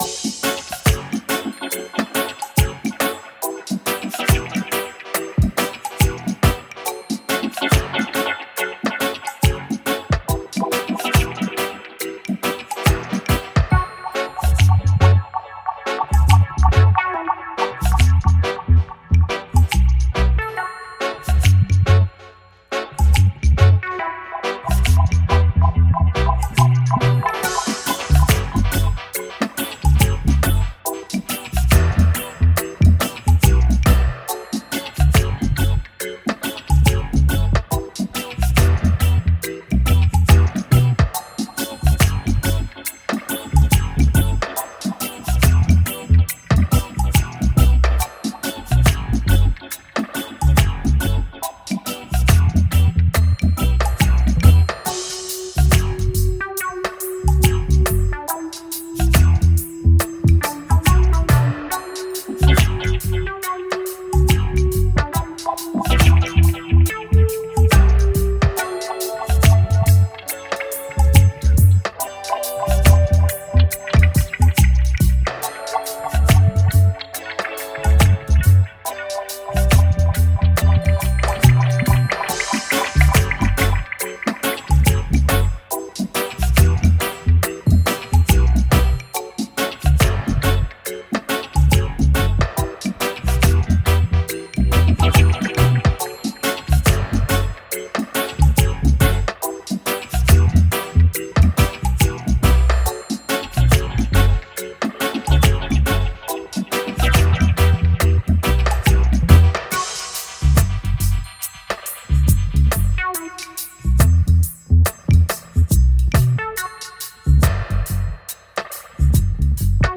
Genre:Reggae
ベース：厚みがあり温かみのあるローリングエレクトリックベースライン。
ギター＆キーボード：象徴的なスカンキングギター、バブリングするハモンドオルガン、ソウルフルなキーボードコンピング。
ホーン：サックス、トランペット、トロンボーンを揃えたフルセクションで、ライブレゲエ録音の本質を表現。
デモサウンドはコチラ↓
Tempo Range: 70 bpm
Key: F#m